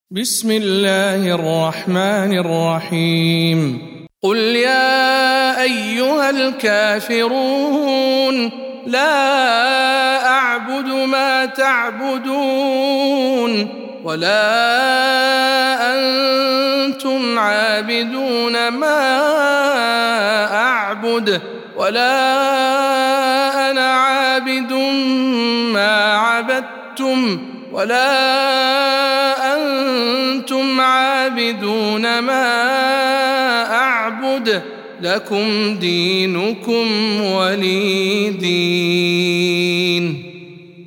سورة الكافرون - رواية ابن ذكوان عن ابن عامر